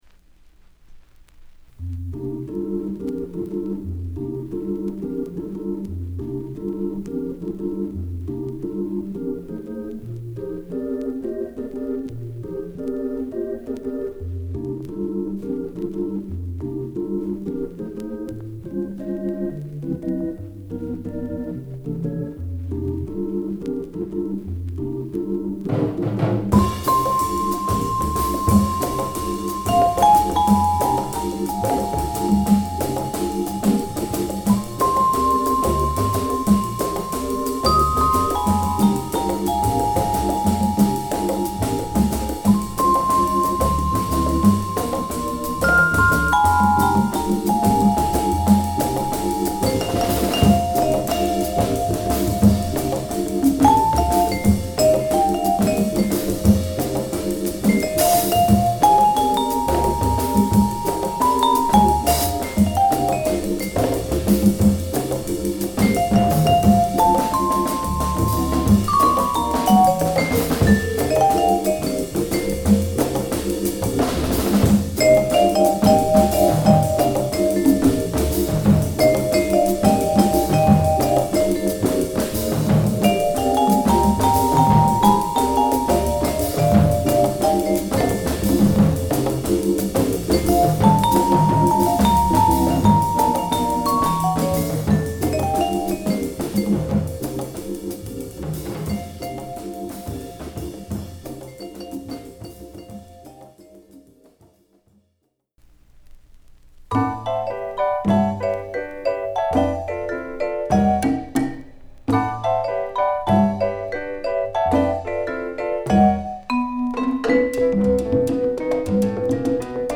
クールなラテン・ジャズを収録！